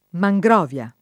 mangrovia [ ma jg r 0 v L a ]